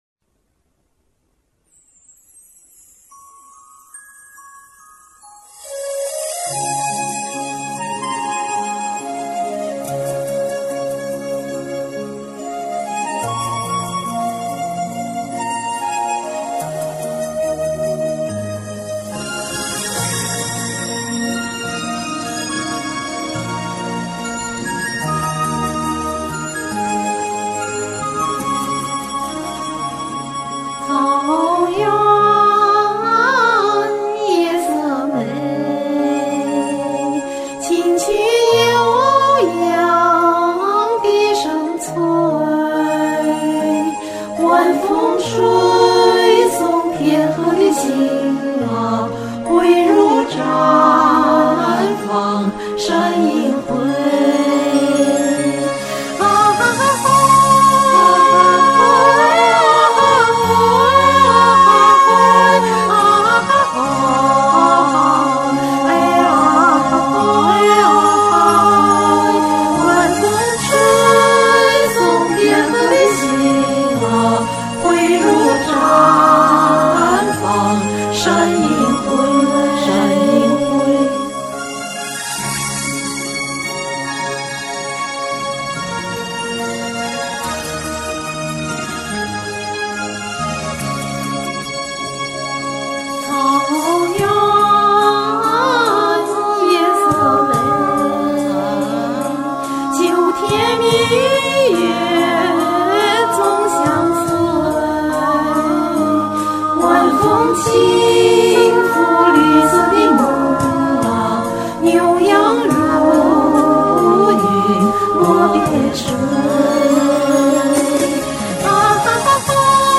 雖然，伴奏質量一般。
這版是新版，比刮跑的那版，多唱了四軌。